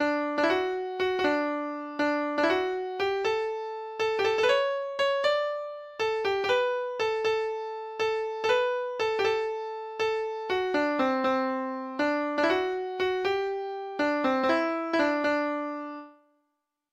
Gamalstev frå Setesdal
Lytt til data-generert lydfil